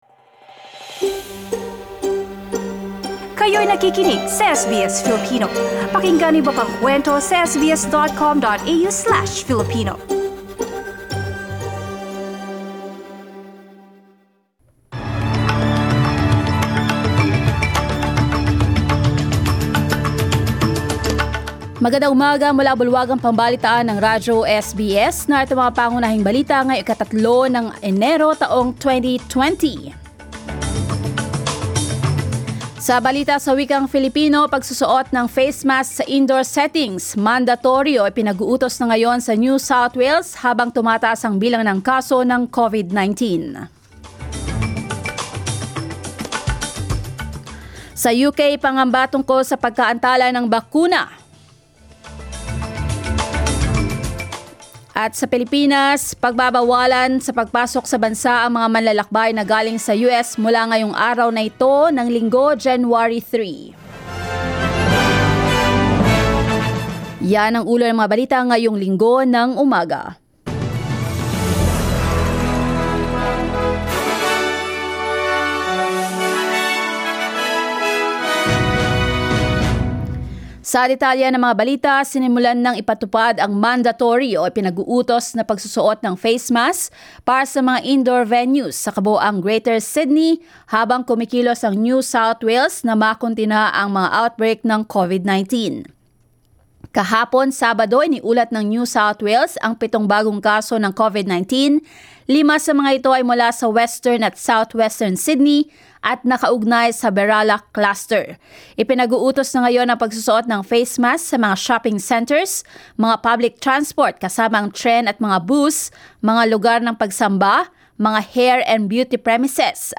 Here are today's top stories on SBS Filipino.